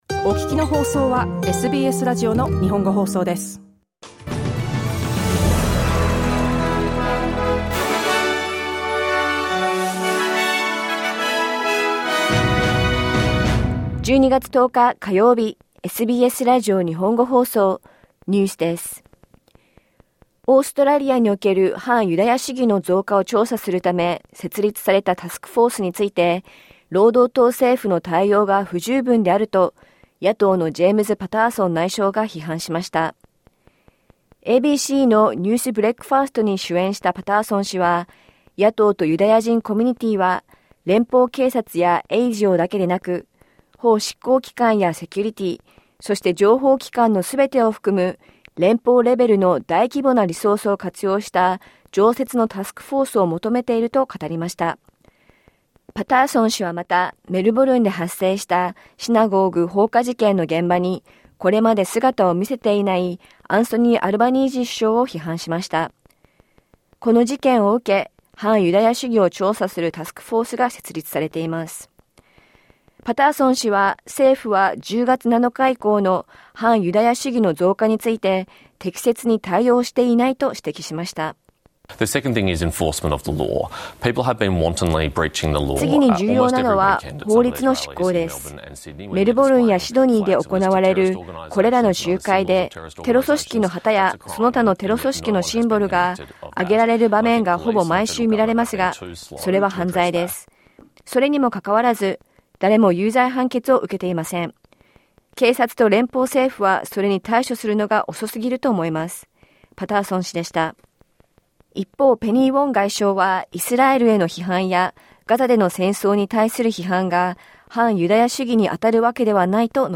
反ユダヤ主義の増加を調査するため、設立されたタスクフォースについて、労働党政府の対応が不十分であると批判が高まっています。5歳未満の子どもがいる家庭の生活費は、2021年と比べて、27％増加していることが、新たな調査で分かりました。午後１時から放送されたラジオ番組のニュース部分をお届けします。